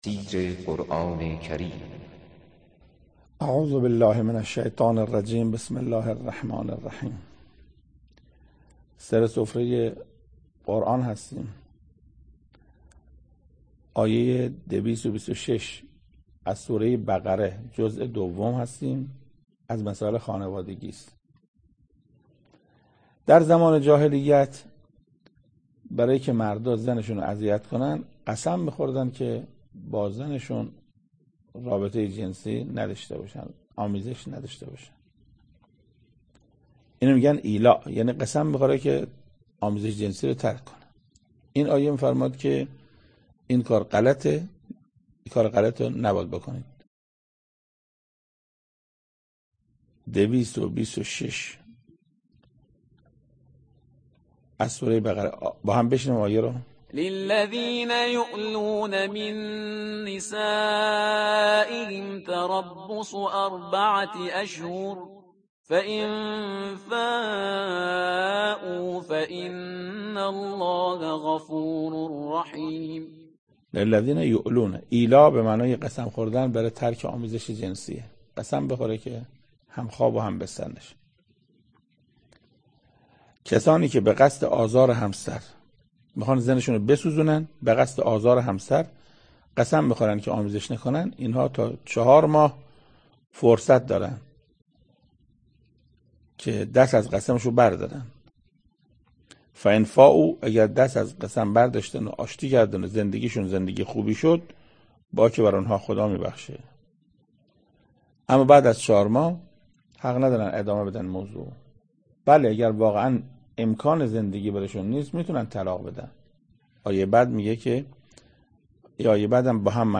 تفسیر دویست و بیست و ششم و دویست و بیست و هفتمین آیه از سوره مبارکه بقره توسط حجت الاسلام استاد محسن قرائتی به مدت 7 دقیقه